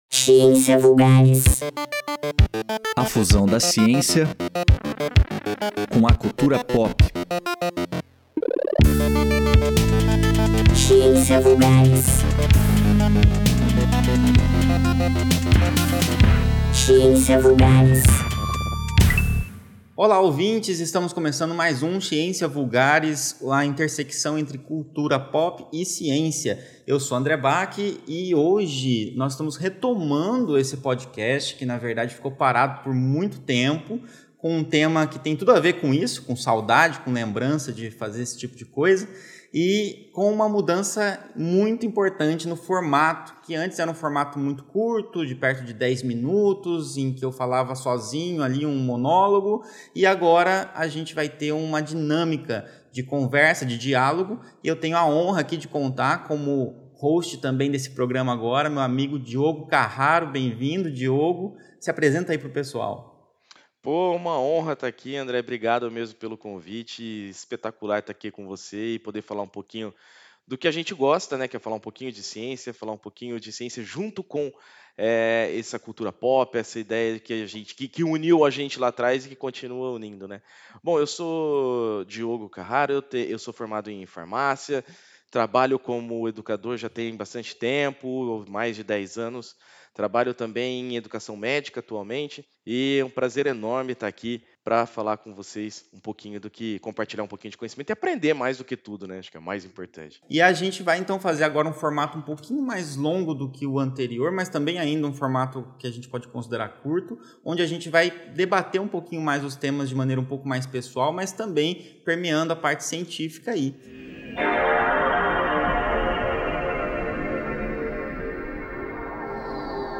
Nosso bate-papo nos leva por um caminho descontraído de memórias pessoais e experiências nostálgicas, revelando como a cultura pop de décadas passadas continua a ressoar conosco hoje. Aprofundamo-nos na ciência por trás da nostalgia, discutindo estudos que destacam os efeitos positivos dessa emoção complexa na saúde mental, no fortalecimento dos laços sociais e no nosso bem-estar psicológico.